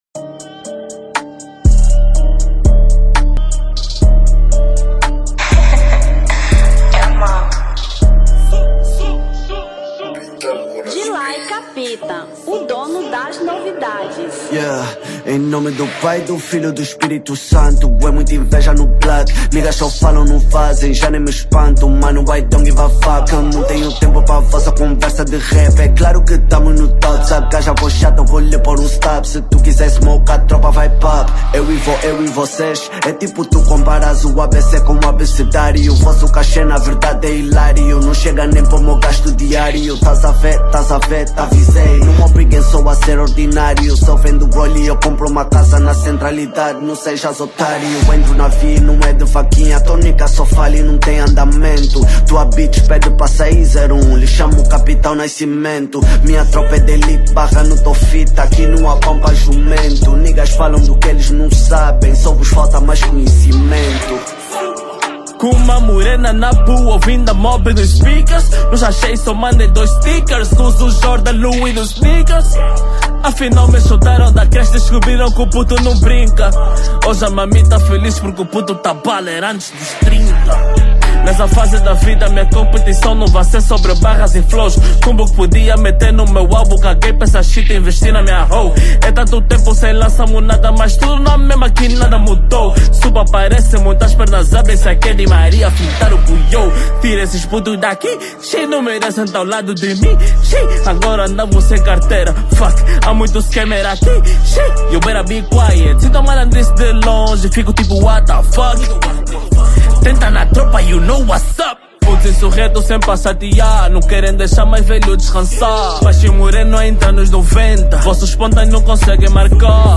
Rap 2025